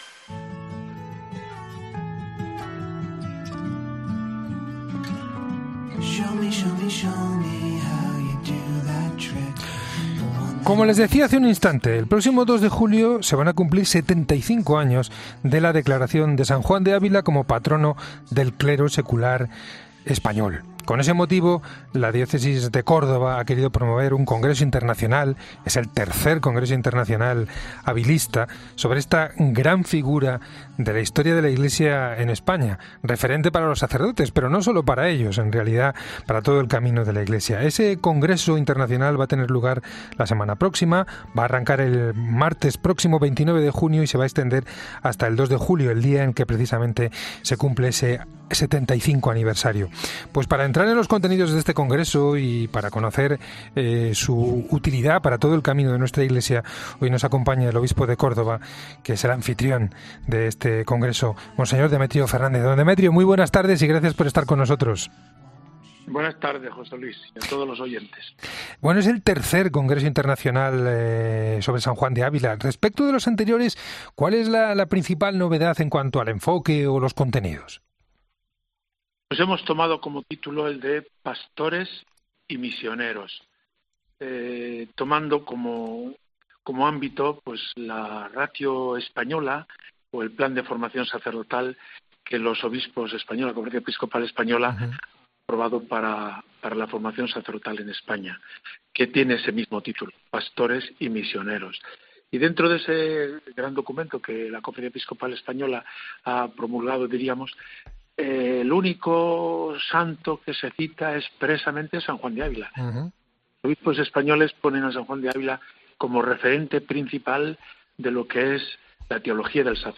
Del 29 de junio al día 2 de julio la Iglesia española celebra el III Congreso Internacional San Juan de Ávila y además el 75 aniversario de su proclamación como patrono del clero español. El obispo de Córdoba Don Demetrio Fernández acude a El Espejo de la Cadena COPE para analizar lo que significa esta celebración.